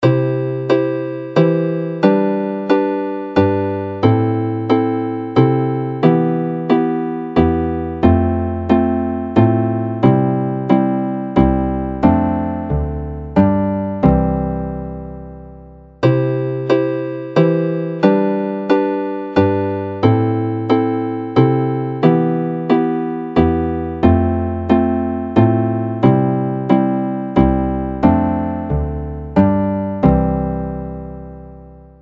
This month, Cynghansail is set in the key of C and is well suited to the harp, flute and whistle.